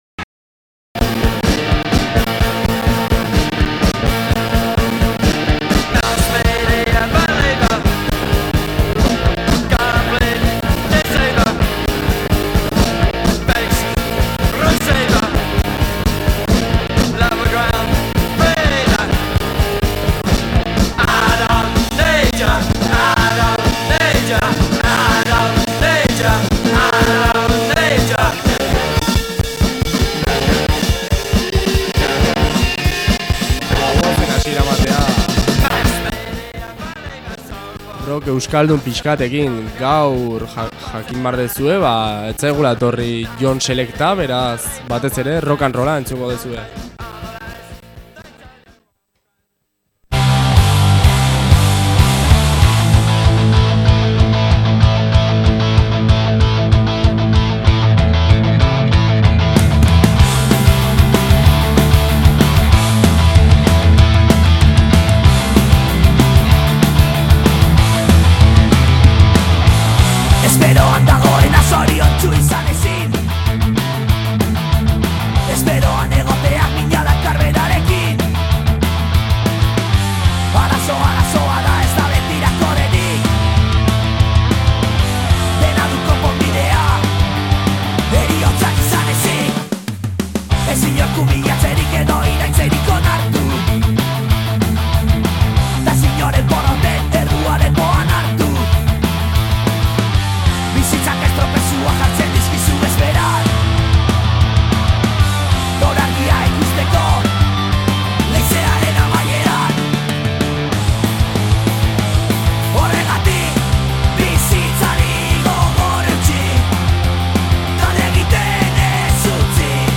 Genero eta soinuekin esperimentatzen.